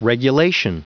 Prononciation du mot regulation en anglais (fichier audio)
Prononciation du mot : regulation